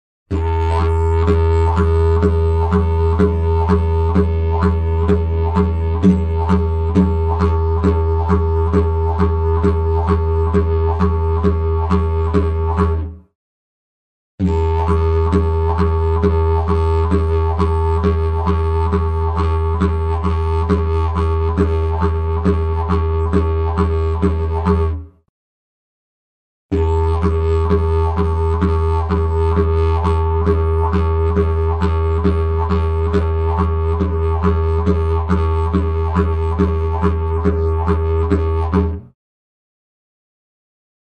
Ta – Ta Ka Ta – Ka Ta Ka
Sample n°37 contiene: esecuzione del double tonguing.